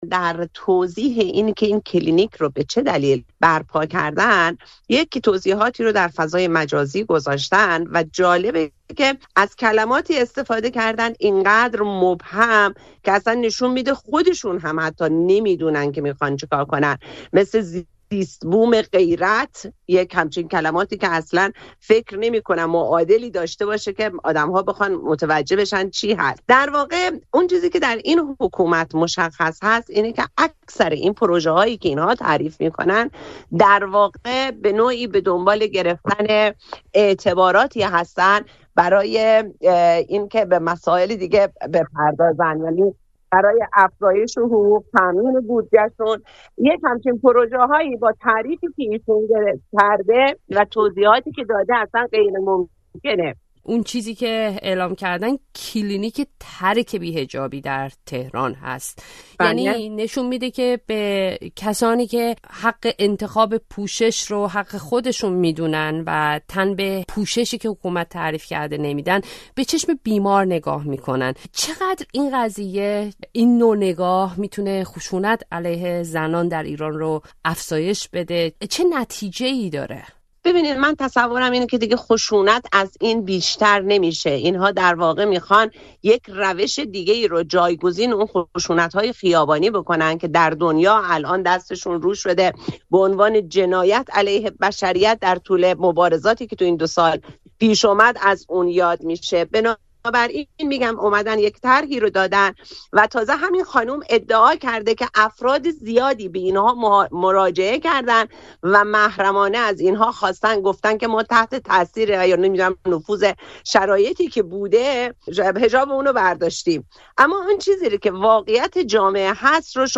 «کلینیک ترک بی‌حجابی» در گفت‌وگو